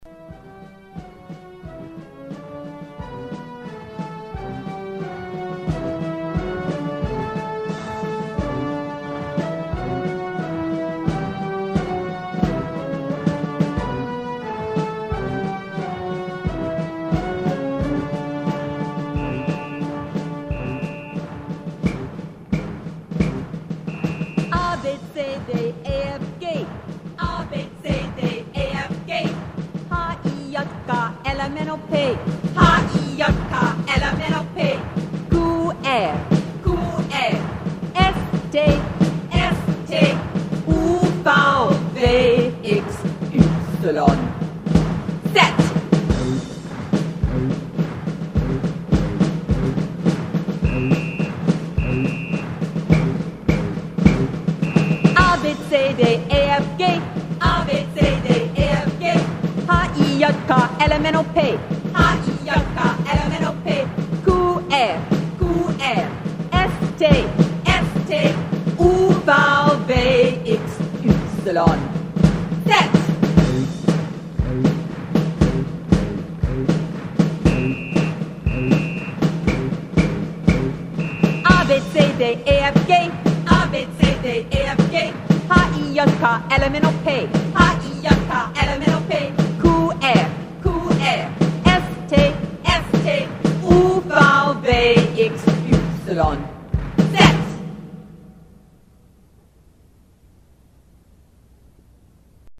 Here are two versions of the alphabet song in German: the first is an MP3 file (audio only) in the marching/military style; the other is a YouTube video with the alphabet sung to a popular tune. Tag01-Alphabet_Song-Marching_Alphabet.mp3 – Alphabet Song Marching Style (MP3) German Alphabet Song Video – Alphabet Song on YouTube
Tag01-Alphabet_Song_2-Marching_Alphabet.mp3